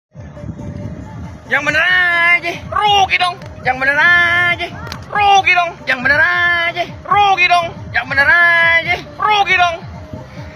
Kategori: Suara viral
Keterangan: Suara Rugi Dong, Yang Bener Aje Meme adalah tren populer di media sosial Indonesia, digunakan untuk video lucu atau sindiran dengan efek suara khas.